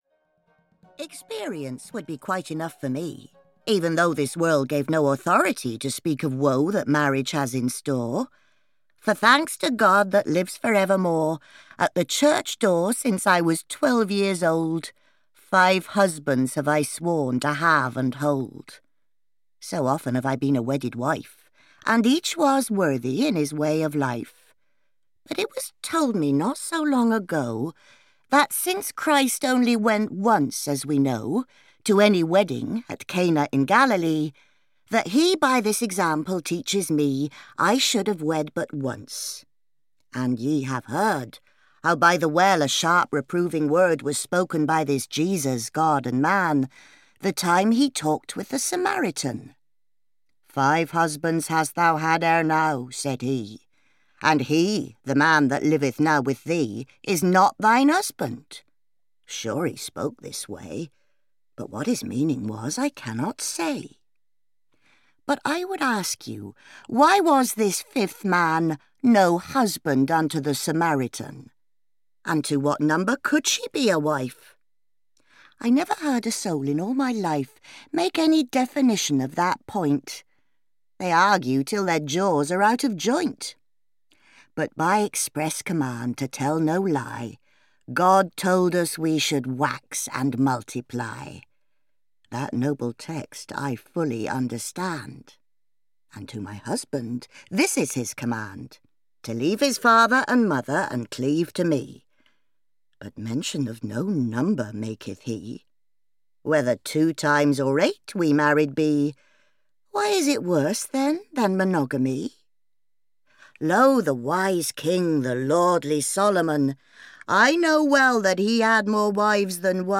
Audiobook The Canterbury Tales II, written by Geoffrey Chaucer.
Ukázka z knihy